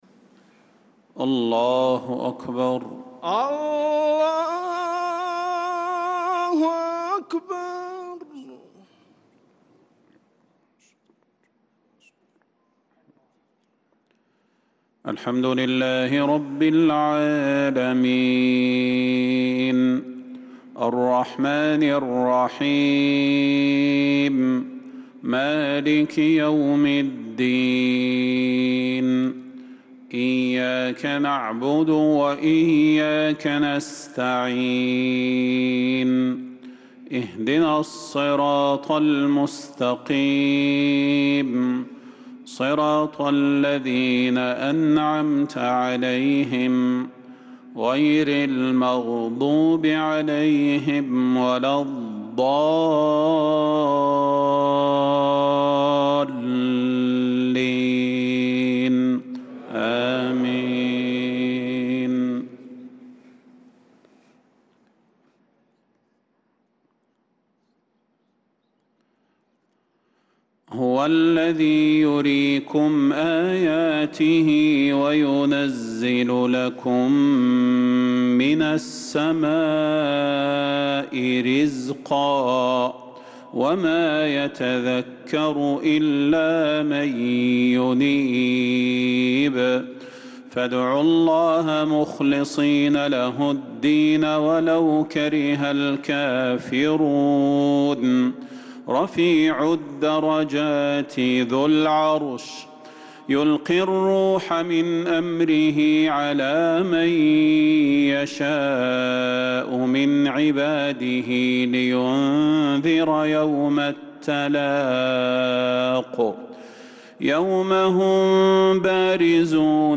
صلاة العشاء للقارئ صلاح البدير 19 رجب 1446 هـ
تِلَاوَات الْحَرَمَيْن .